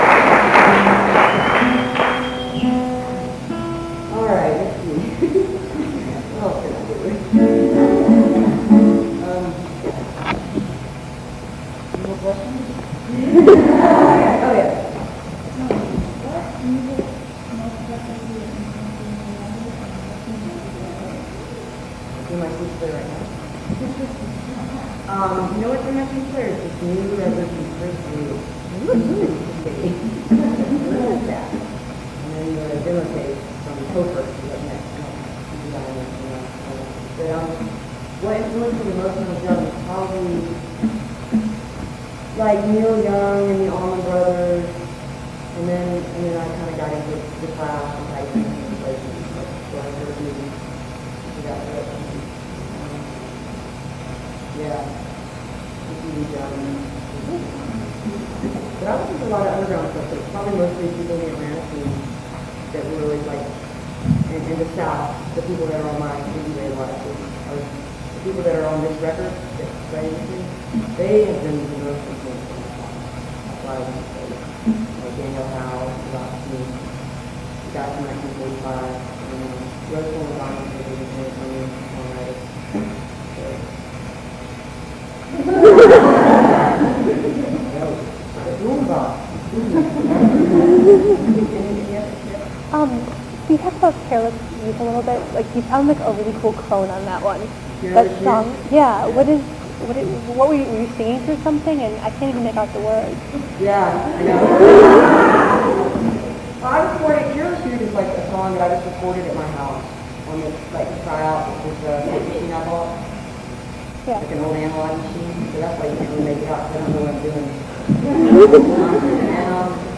lifeblood: bootlegs: 2001-08-13: miramar theater for atomic records - milwaukee, wisconsin (in store appearance by amy ray)
06. talking with the crowd (2:37)